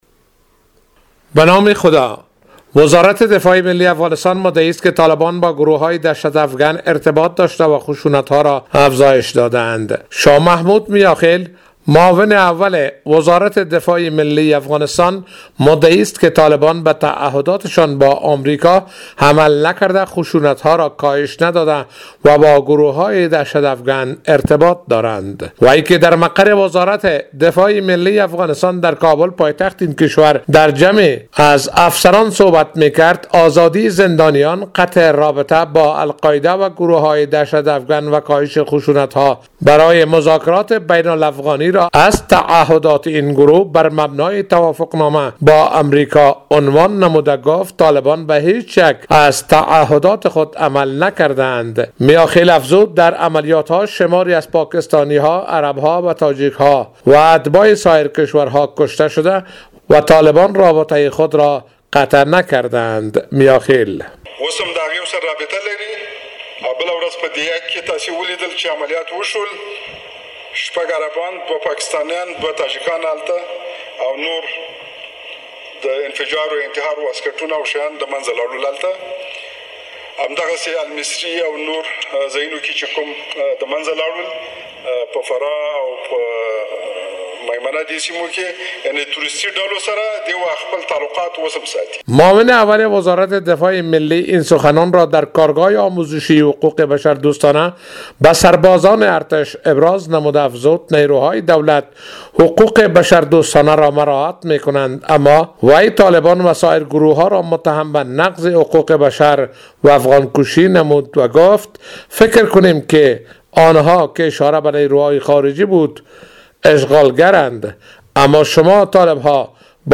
به گزارش خبرنگار رادیو دری،شاه محمودمیاخیل معاون اول وزارت دفاع ملی افغانستان مدعی است که طالبان به تعهدات شان با آمریکا عمل نکرده وبا گروه های دهشت افگن ارتباط دارند.